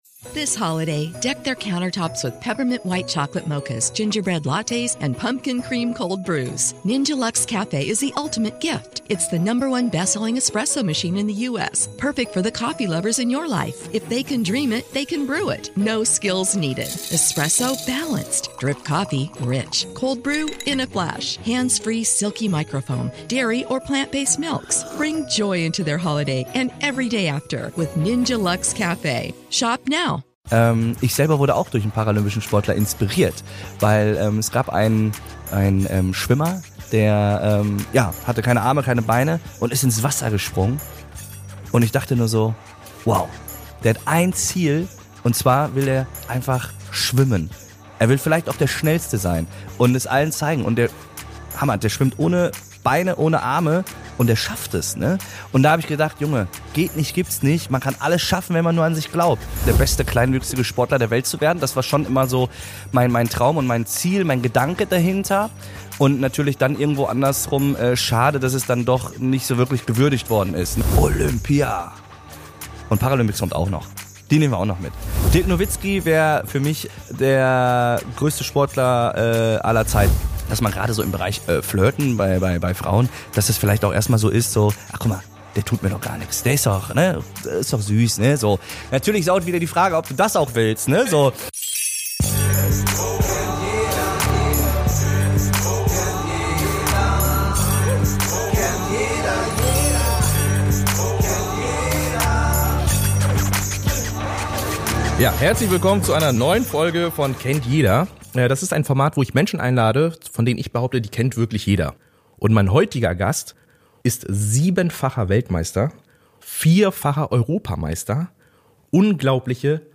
Ein offenes, ehrliches und sehr unterhaltsames Gespräch mit einem echten Vorbild - auch wenn er sich selber so nicht bezeichnen möchte...